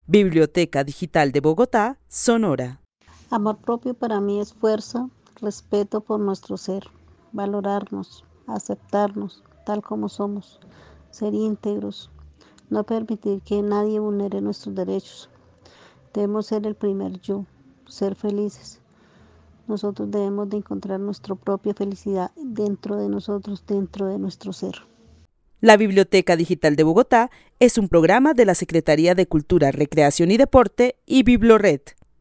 Narración oral de una mujer que vive en la ciudad de Bogotá y entiende el amor propio como fuerza, respeto y aceptación para valorarse a sí misma. Resalta que la felicidad está dentro de cada mujer. El testimonio fue recolectado en el marco del laboratorio de co-creación "Postales sonoras: mujeres escuchando mujeres" de la línea Cultura Digital e Innovación de la Red Distrital de Bibliotecas Públicas de Bogotá - BibloRed.